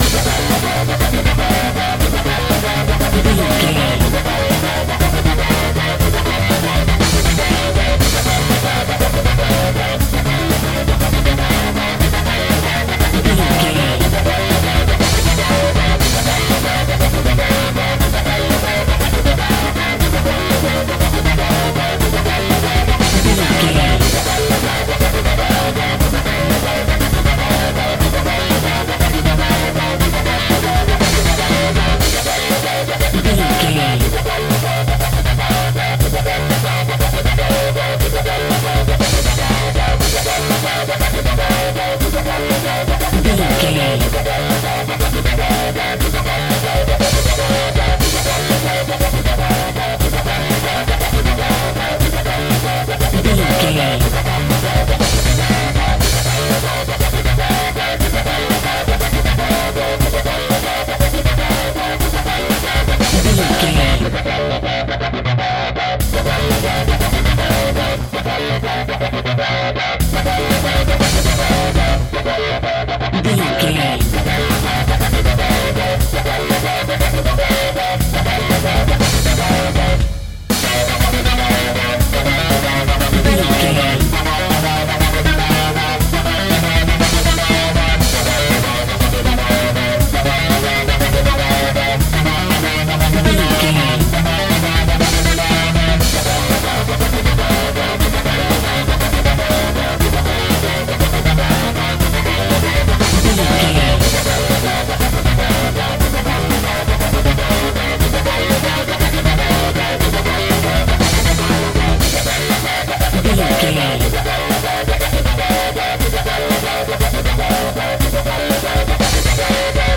Epic / Action
Fast paced
Aeolian/Minor
intense
high tech
futuristic
energetic
driving
repetitive
dark
synthesiser
drum machine
breakbeat
power rock
electronic
synth lead
synth bass